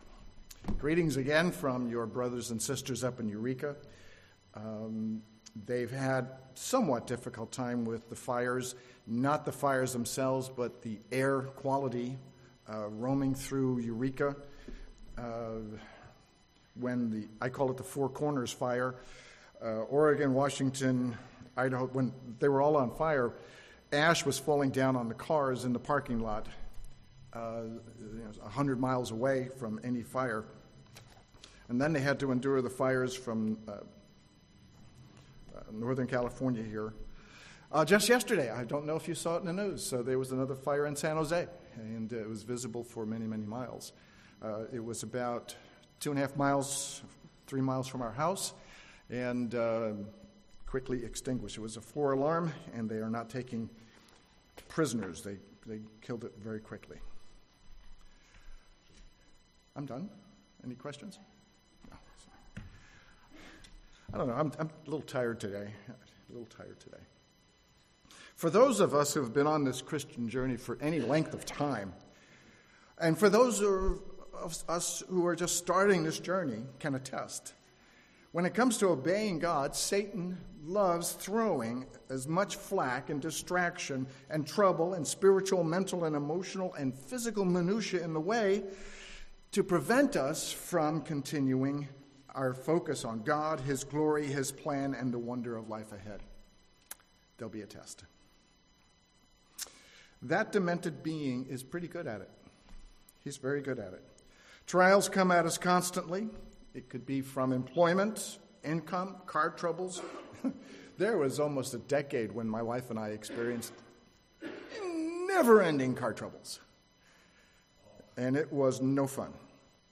A large variety of trials come at us constantly. Sometimes we wonder when and if God will intervene and relieve our stress and misery. Listen to this sermon to find out why we should wait for God’s answer and blessing instead of trying to manufacture our own self-willed solutions.